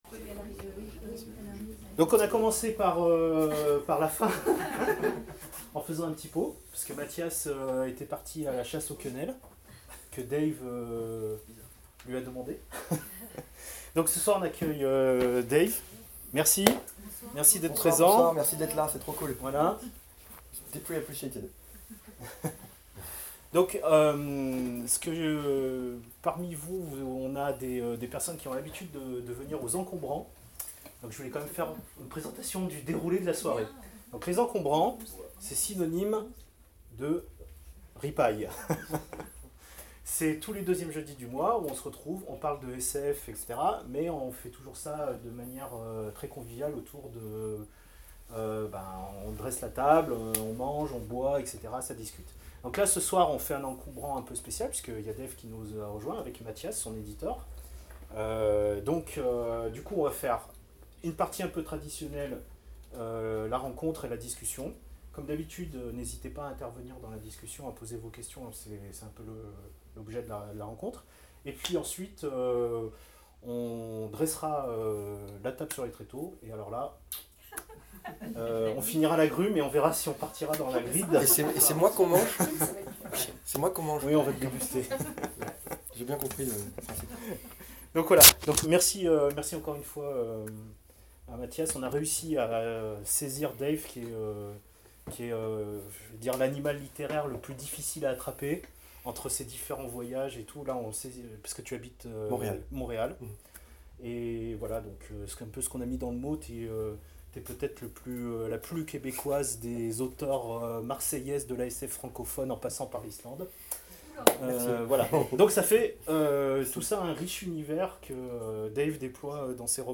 à la librairie L'Esprit Livre de Lyon.
Interview